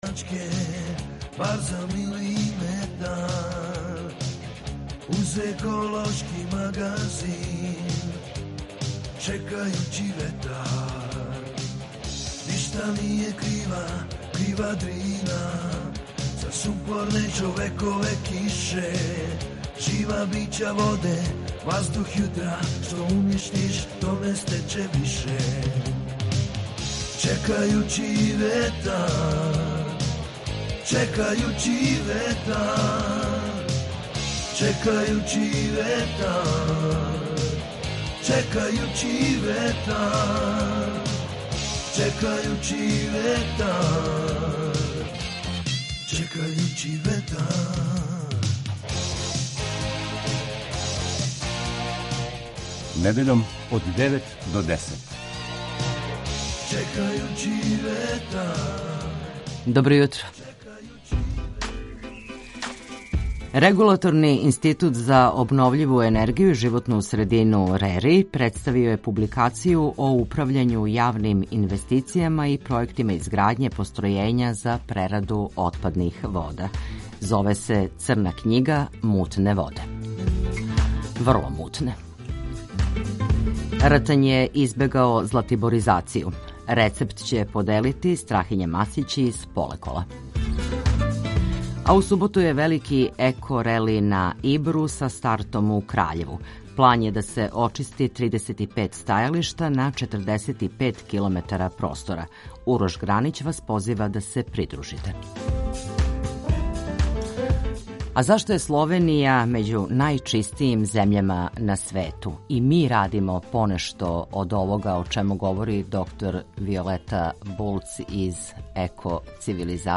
ekološki magazin Radio Beograda 2 koji se bavi odnosom čoveka i životne sredine, čoveka i prirode.